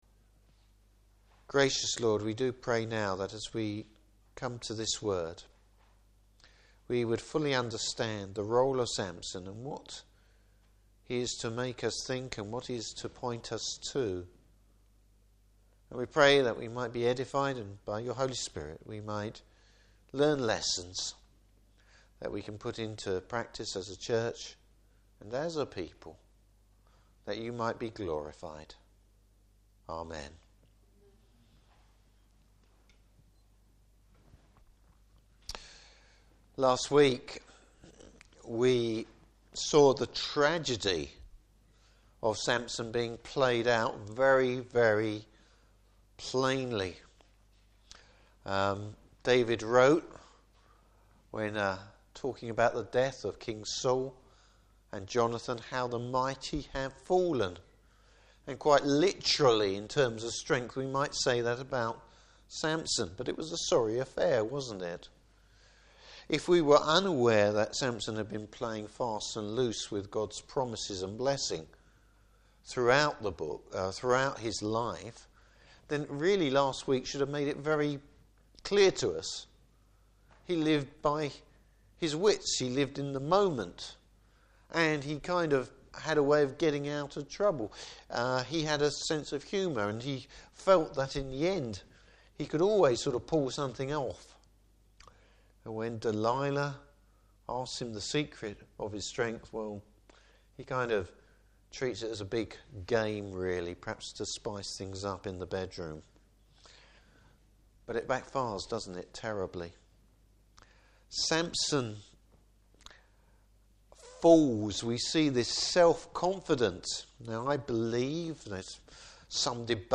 Service Type: Evening Service Bible Text: Judges 16:23-31.